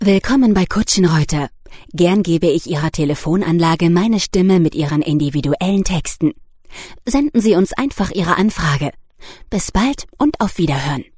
Im Studio werden Ihre Ansagen individuell für Sie produziert – KI-generierte Ansagen oder Texte ‚von der Stange‘ gibt’s bei uns nicht.